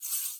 snd_batdie.ogg